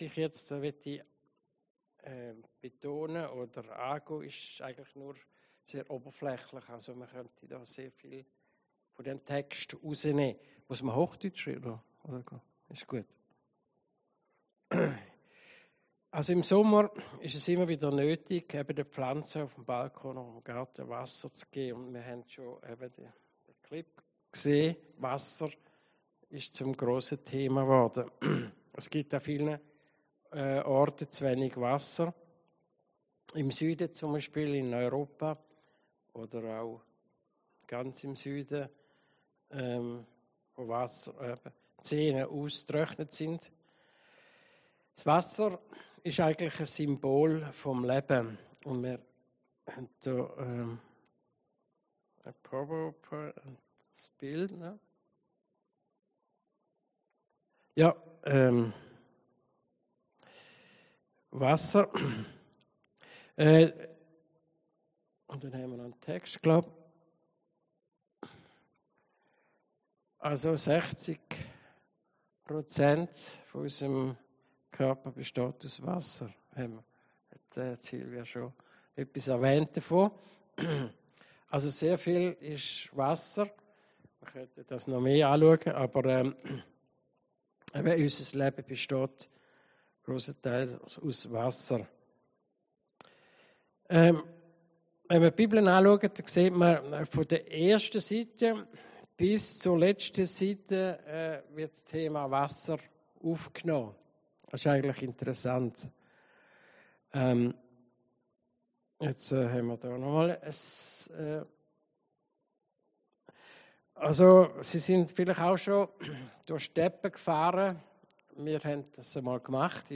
Predigten Heilsarmee Aargau Süd – Lebendiges Wasser